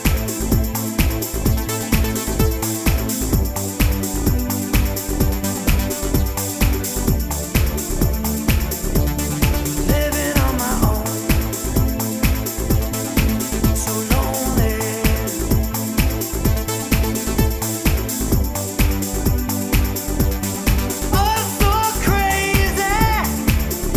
Single Mix Pop (1980s) 3:29 Buy £1.50